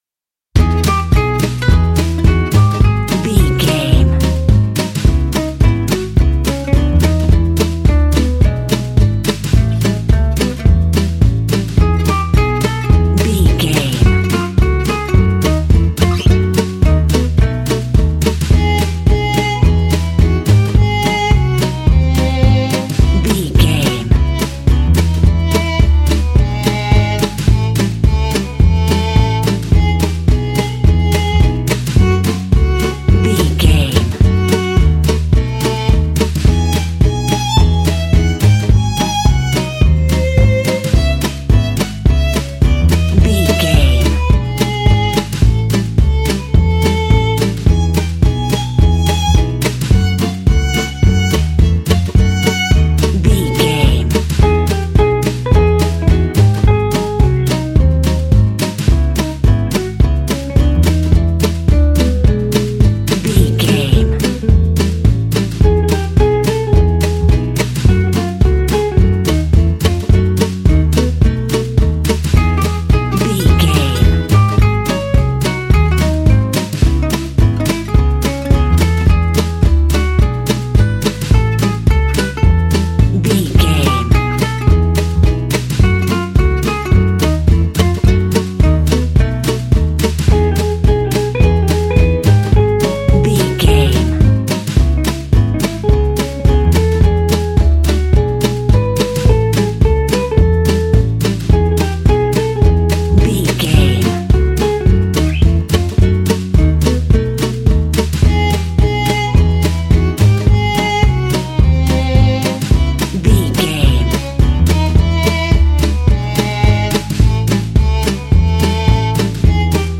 A smooth and sexy piece of jazz music.
Smooth jazz piano mixed with jazz bass and cool jazz drums.
Fast
jazz piano
double bass
swinging